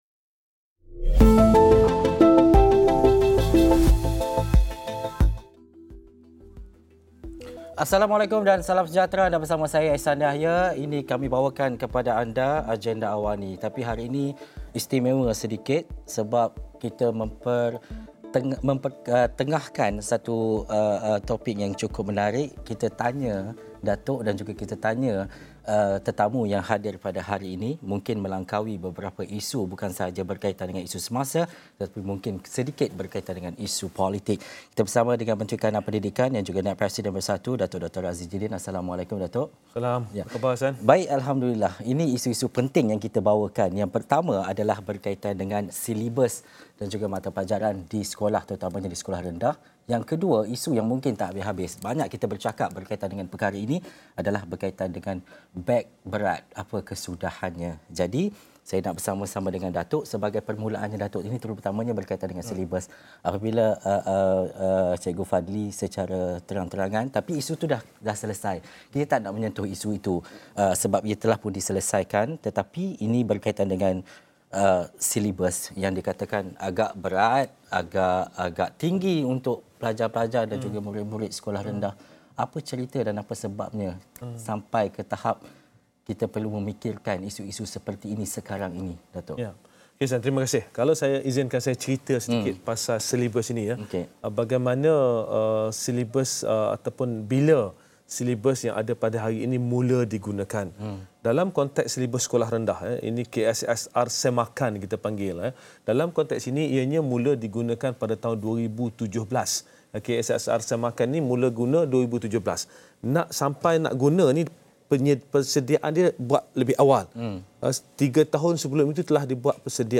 Ikuti temu bual bersama Menteri Kanan Pendidikan, Datuk Dr. Radzi Jidin berkaitan pandangan orang ramai mengenai tahap sukatan pembelajaran di sekolah dan usaha untuk mengurangkan beban murid.